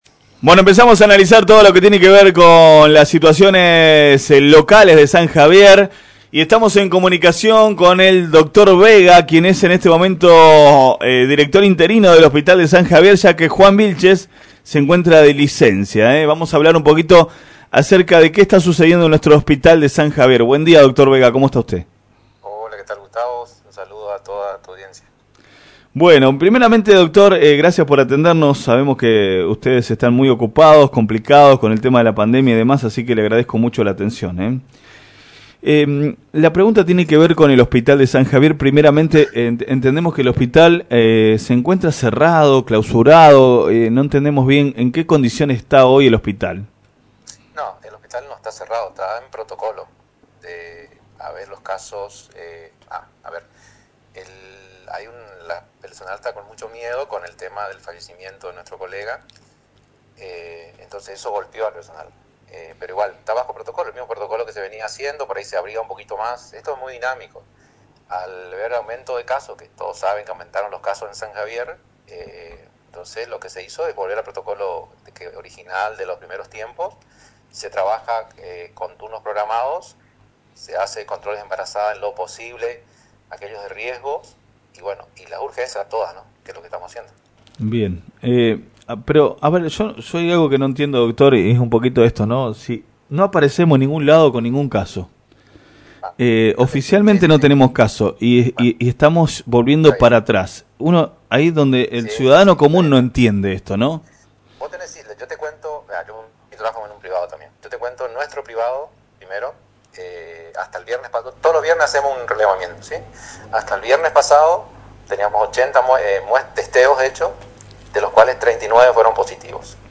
en diálogo con FM Alto Uruguay